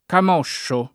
kam0ššo o kam1ššo] s. m.; pl. ‑sci — sim. il cogn. Camosci — con -o- aperto la pn. domin a Fir. e preval. in Tosc.; con -o- chiuso la pn. domin. a Roma, in altre parti del Centro e nella Tosc. sen.; prob. simile la distribuz. dei suoni per camozza, variante di forma indicante oggi la femmina dell’animale; stesse alternanze di pn. nelle forme rizotoniche dei verbi derivati camosciare e scamosciare — etimo il lat. tardo camox, genitivo camocis, con -o- lunga, passato però all’it. attraverso il fr. o qualche dial. settentr., da cui la terminaz. in -oscio (e in -ozza)